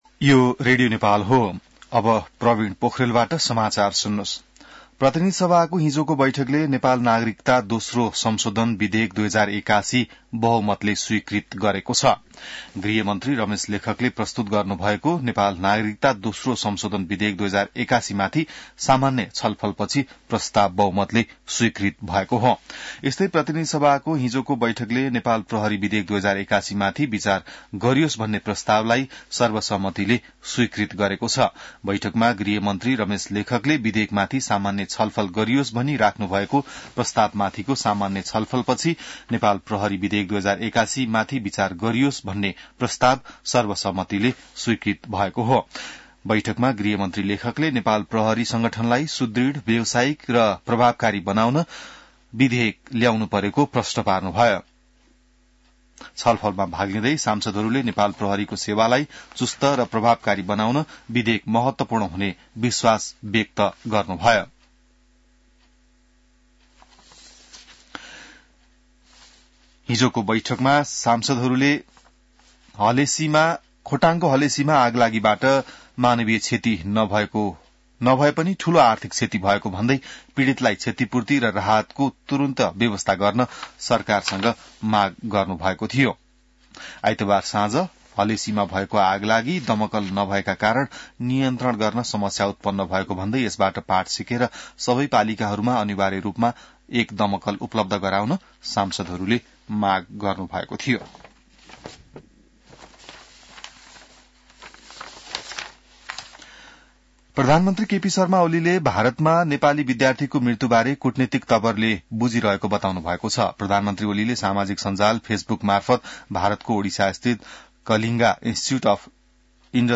बिहान ६ बजेको नेपाली समाचार : ७ फागुन , २०८१